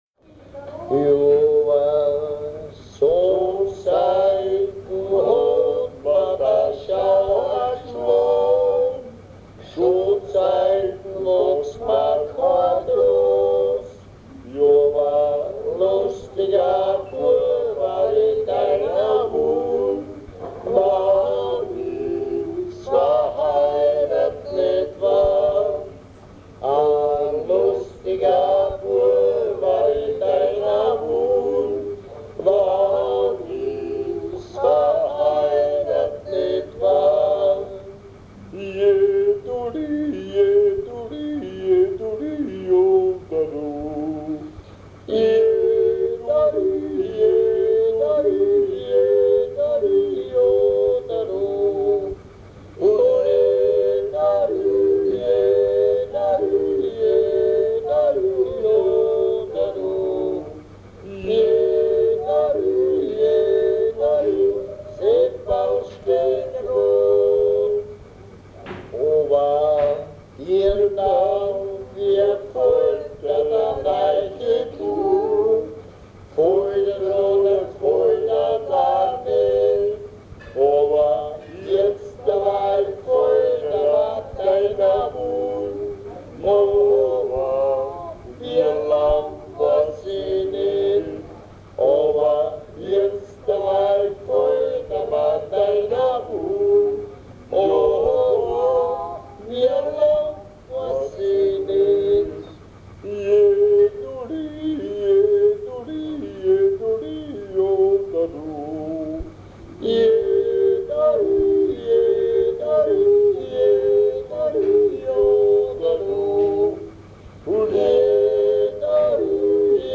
CD 1_Titel 6: WeXel oder Die Musik einer Landschaft Teil 2.1 - Das Weltliche Lied - Ungeradtaktig: Jodler und Jodler-Lied – Ungeradtaktig (E-BOOK - o:1617)